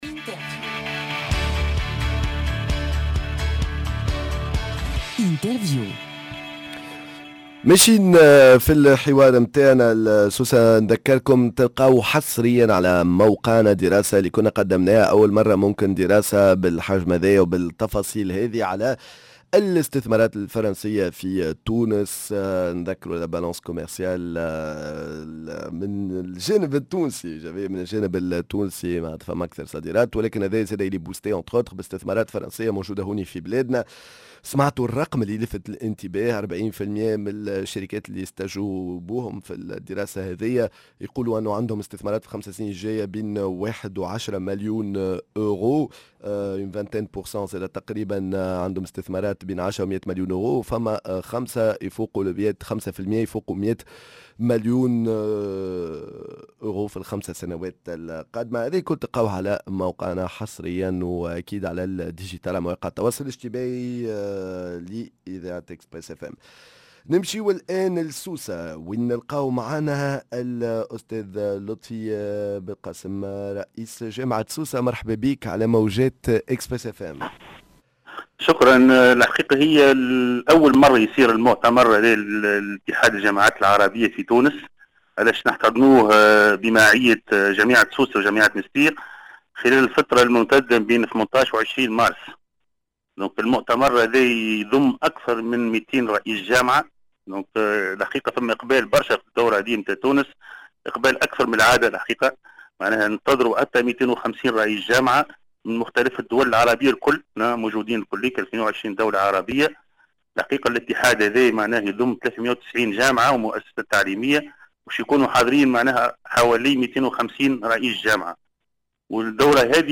L’interview La 55ème édition du congrès de l'union des universités Arabes